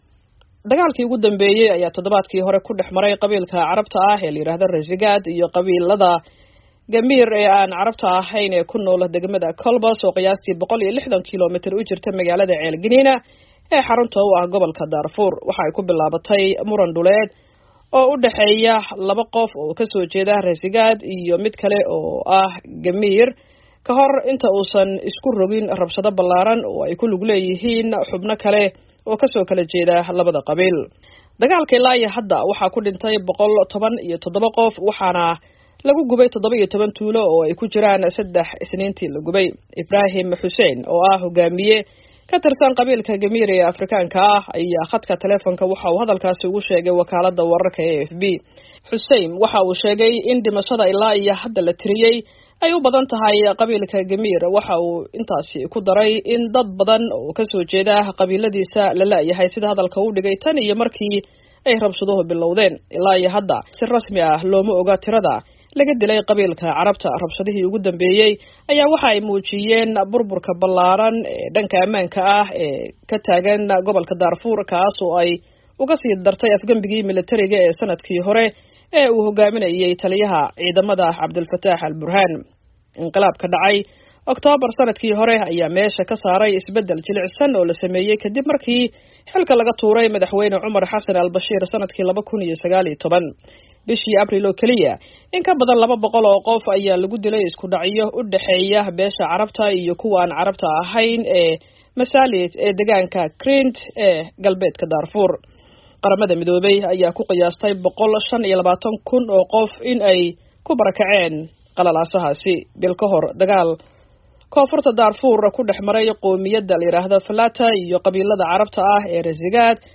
Warbixinta Dagaalka Darfur